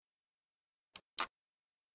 دانلود آهنگ دکمه موس از افکت صوتی اشیاء
دانلود صدای دکمه موس از ساعد نیوز با لینک مستقیم و کیفیت بالا
جلوه های صوتی